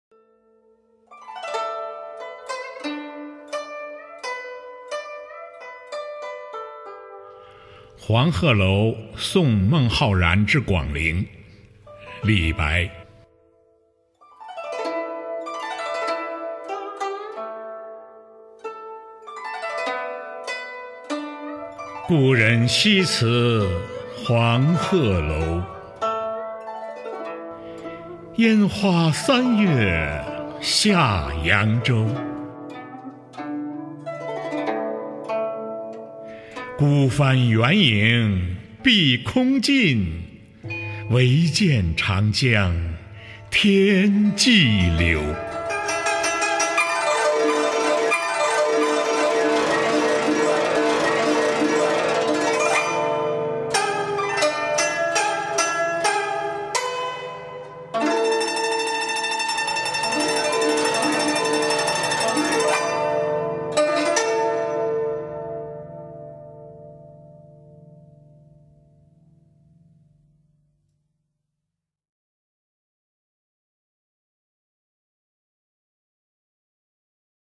[隋唐诗词诵读]李白-黄鹤楼送孟浩然之广陵 唐诗吟诵